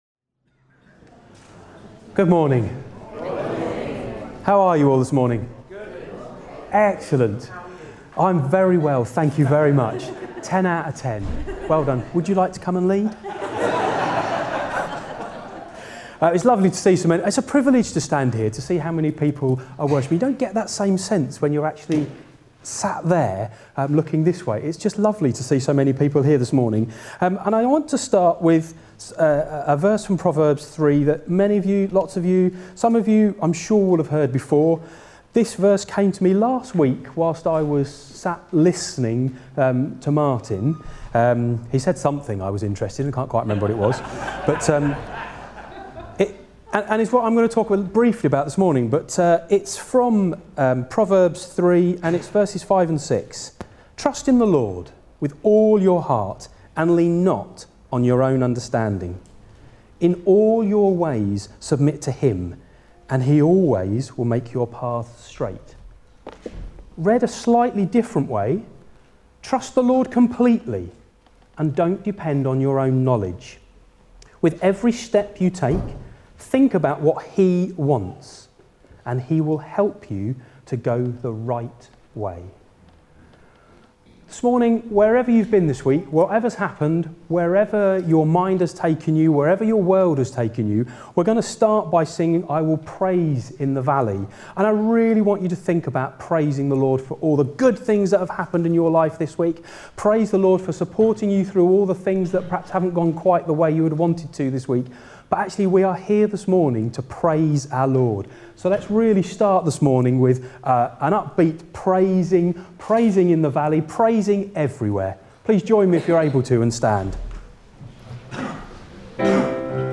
8 March 2026 – Morning Service
Service Type: Morning Service